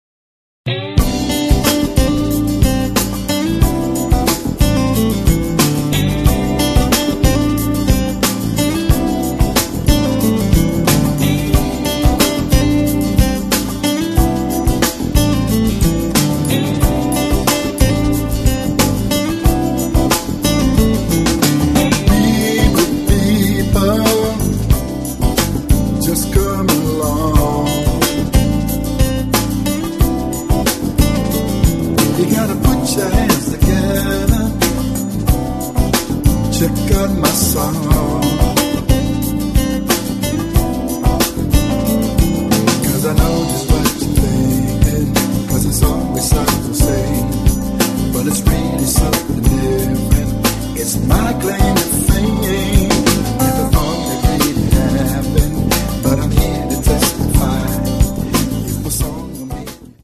Un disco ballabile, anche ad occhi chiusi.